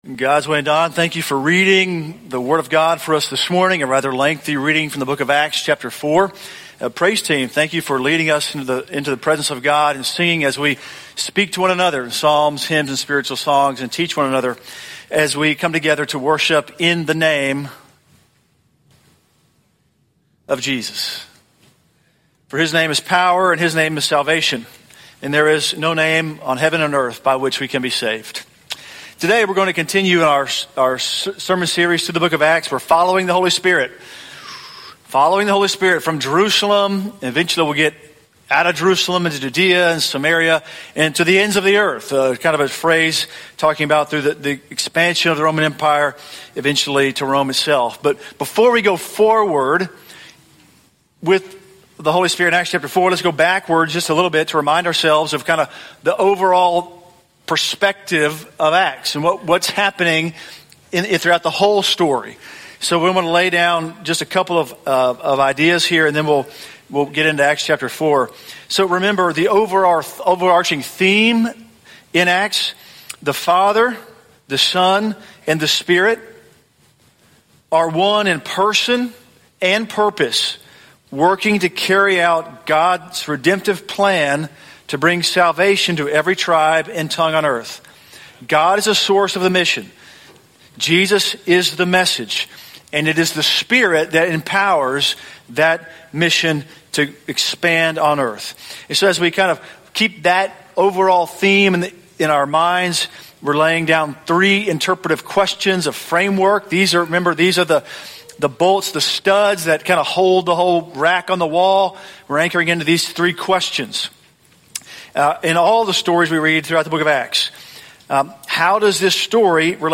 The Spirit Acts: Serving the Sovereign Lord - English Sermons - 10:15